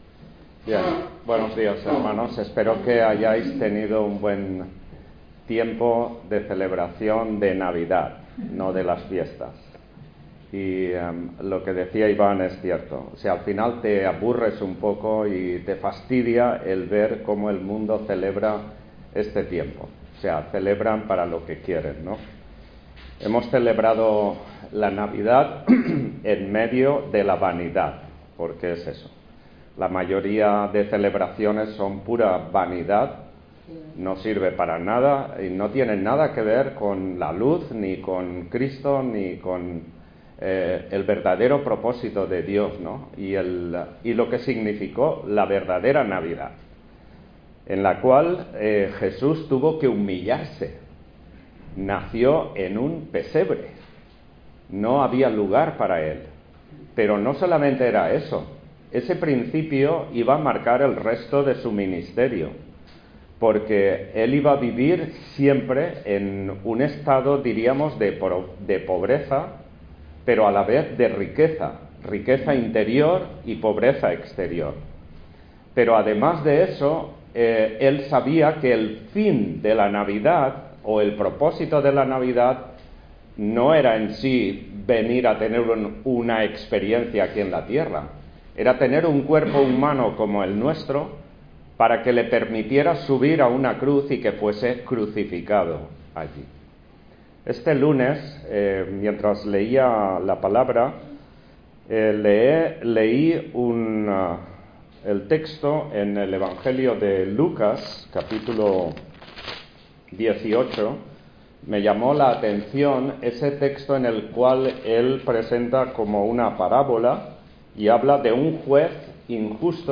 Predicaciones – Página 3 – Redimidos para Adorar
Service Type: Culto Dominical